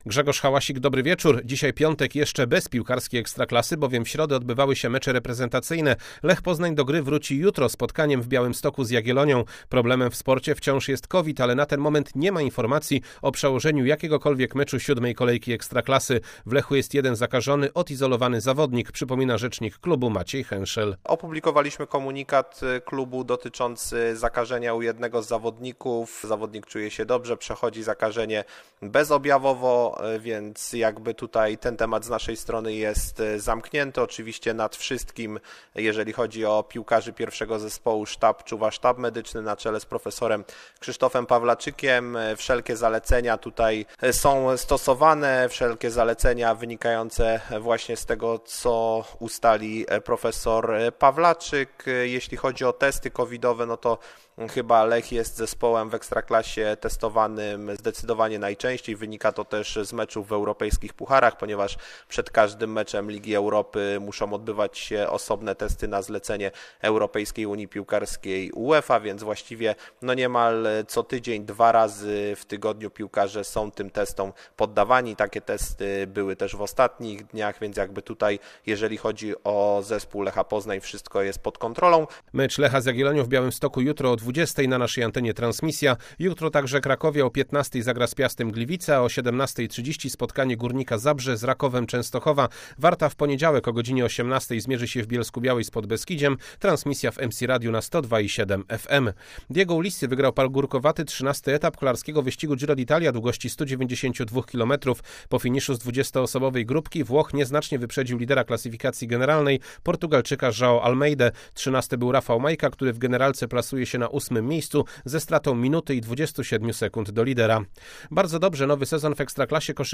16.10. SERWIS SPORTOWY GODZ. 19:05